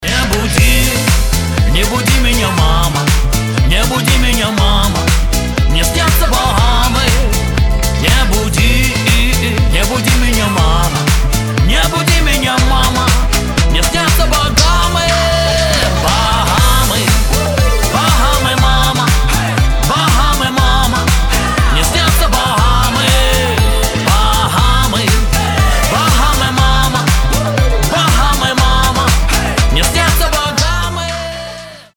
• Качество: 320, Stereo
позитивные
веселые